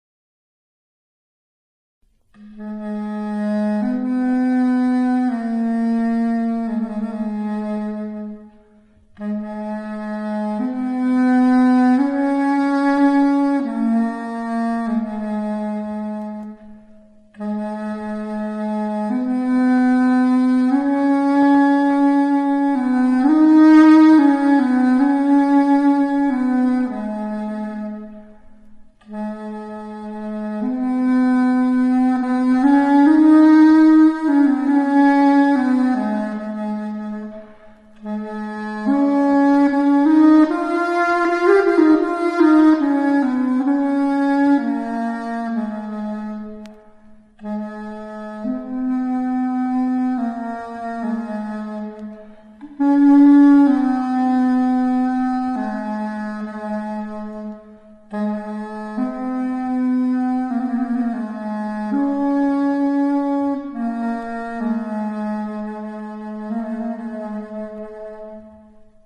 Birbyne:
wooden birbyne